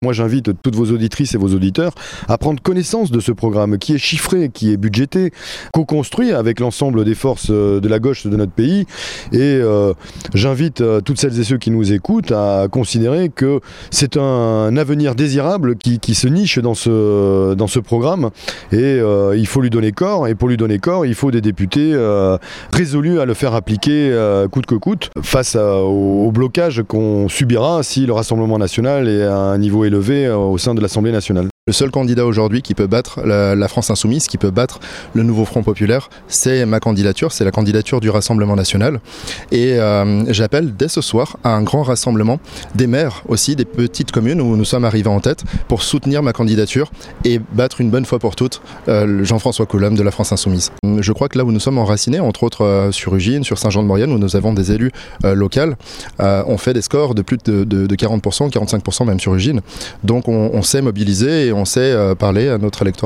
Jean-François Coulomme (Candidat du Nouveau Front Populaire)